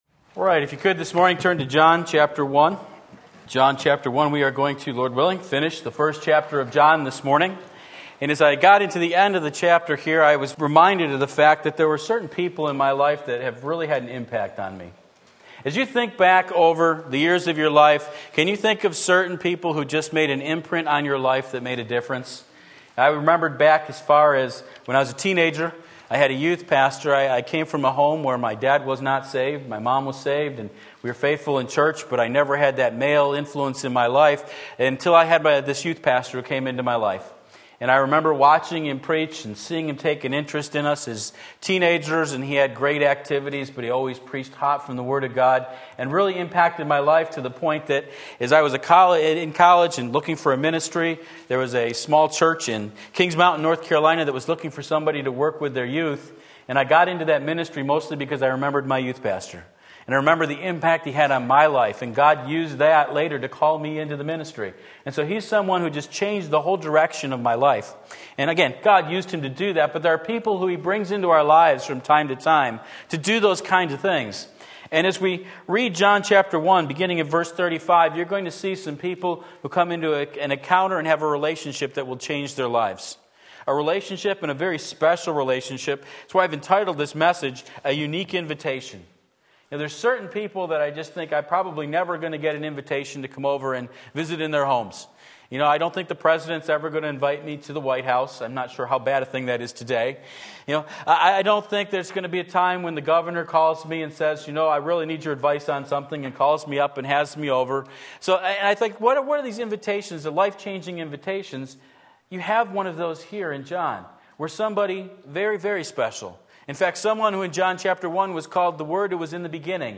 Sermon Link
A Unique Invitation John 1:35-51 Sunday Morning Service, November 13, 2016 Believe and Live!